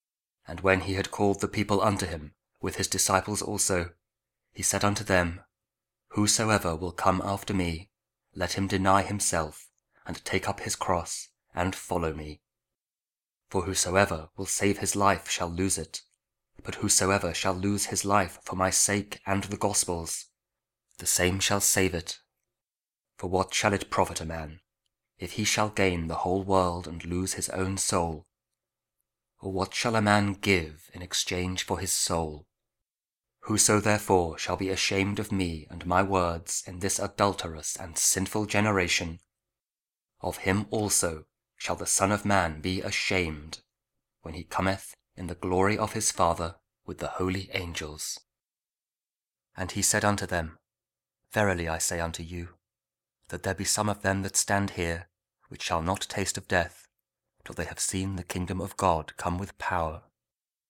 Mark 8: 34 – 9:1 – Week 6 Ordinary Time, Friday (Audio Bible, Spoken Word)